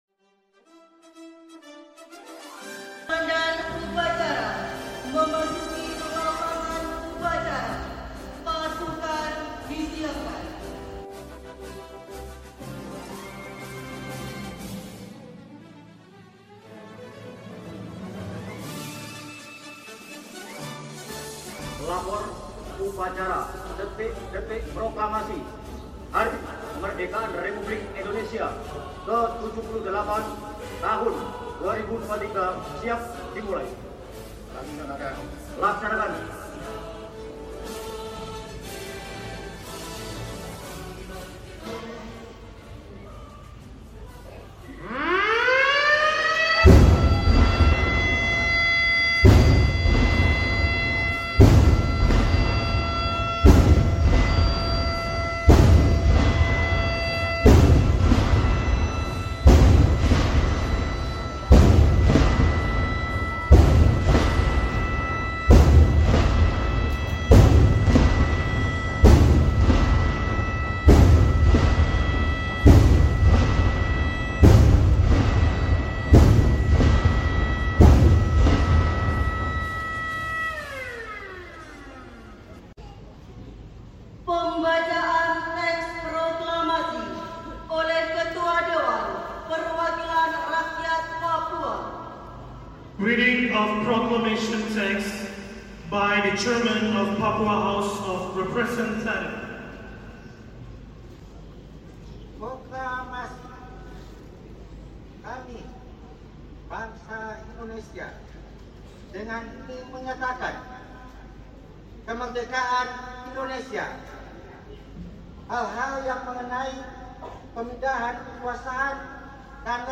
Detik Detik Proklamasi 17 Agustus 2023 Di Stadion Mandala Jayapura